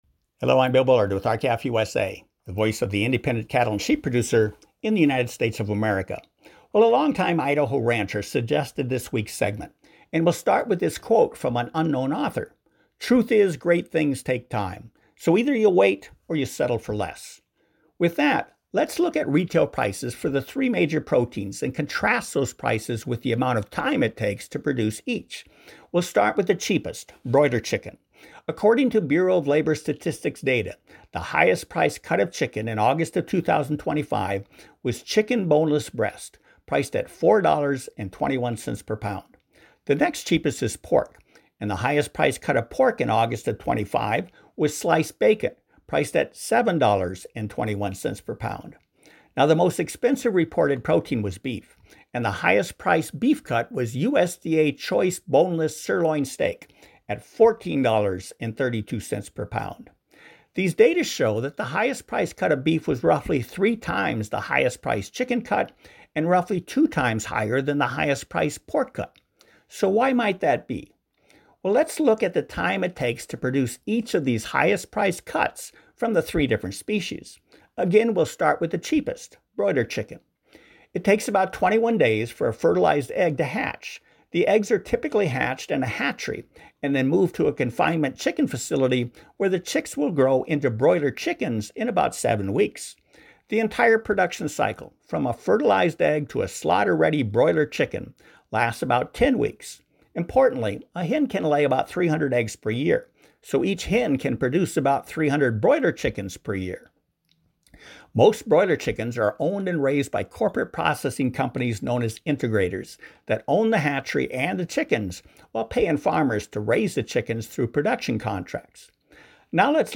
R-CALF USA’s weekly opinion/commentary educates and informs both consumers and producers about timely issues important to the U.S. cattle and sheep industries and rural America.